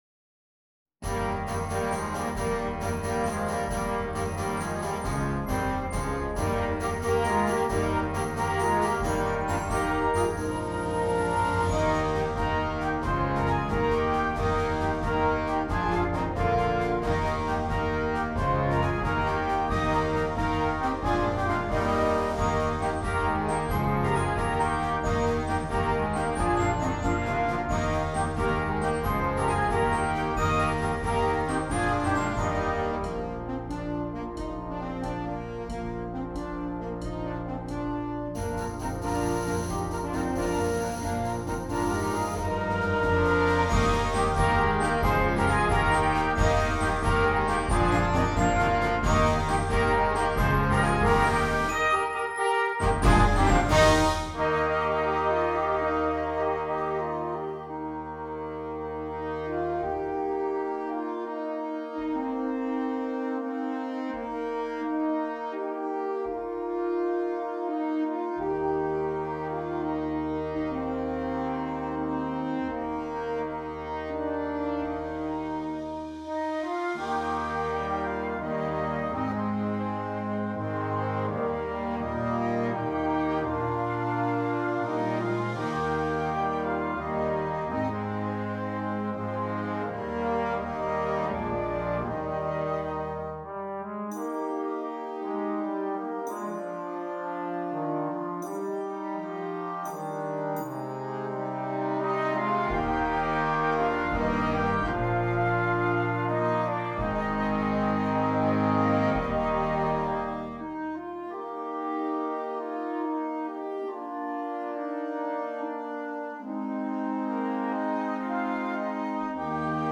Flexible Band